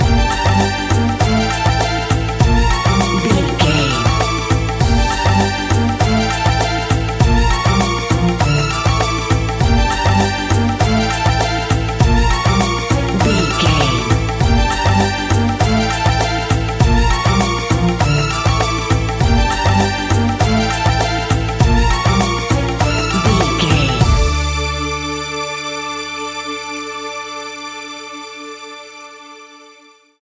Aeolian/Minor
D
World Music
percussion